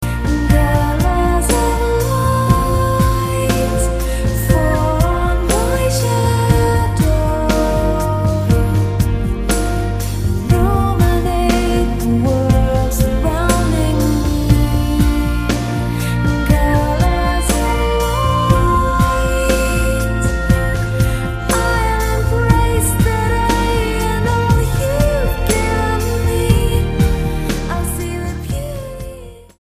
STYLE: Ambient/Meditational
Over Christmas/Epiphany the album sounds very seasonal.